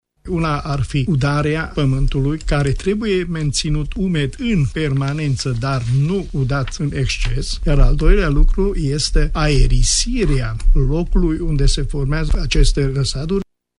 specialist în horticultură